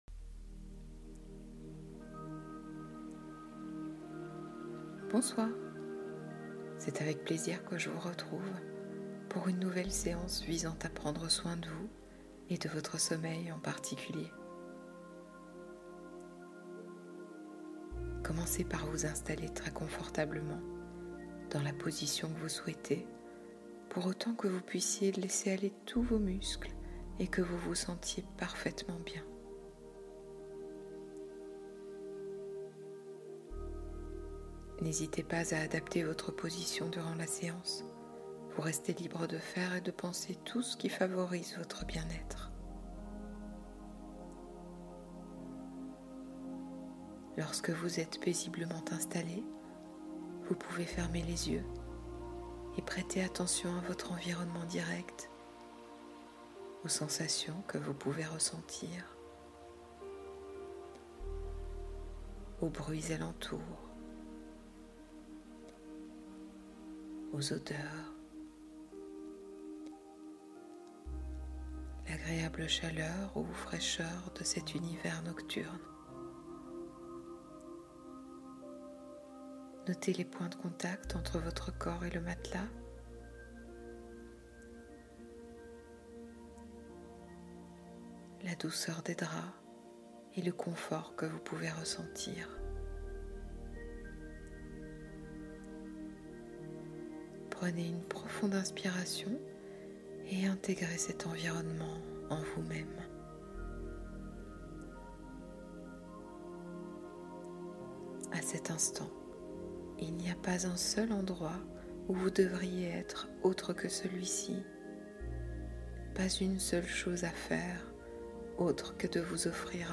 Sommeil au cœur des étoiles : hypnose réparatrice